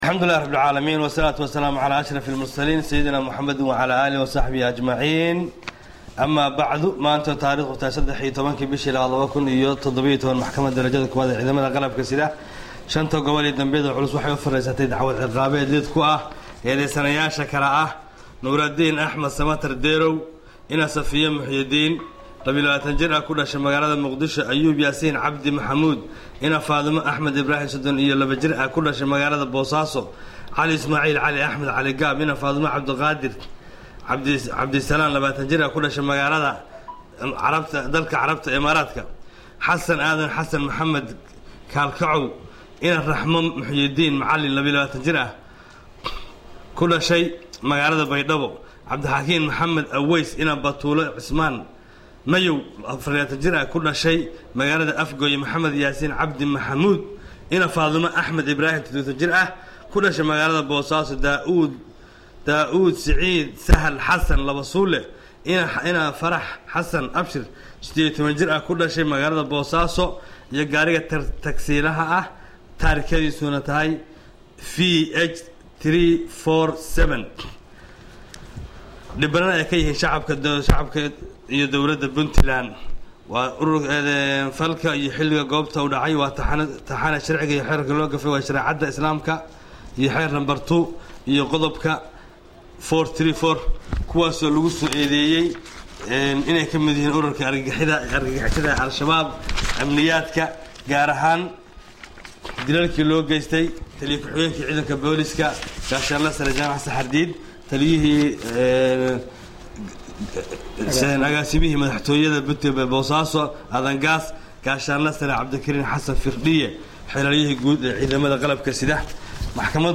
codka-maxkamada-dilka.mp3